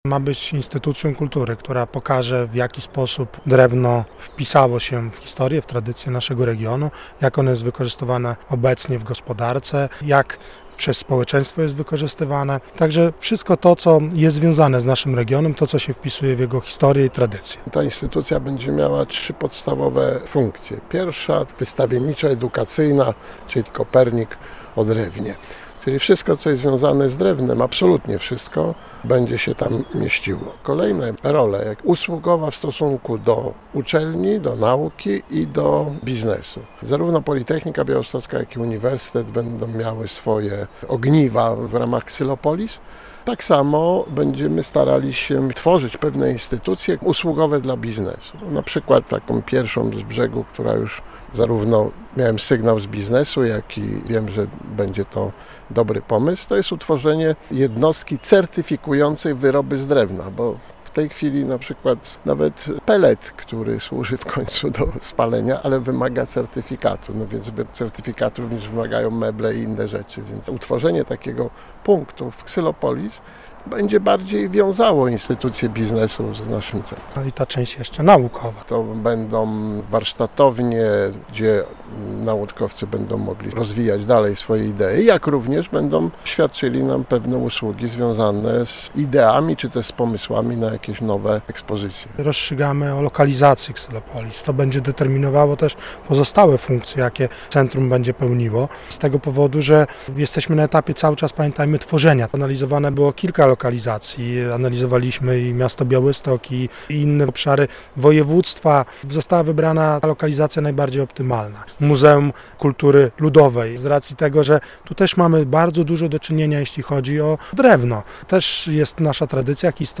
Na terenie Podlaskiego Muzeum Kultury Ludowej powstanie Centrum Xylopolis - relacja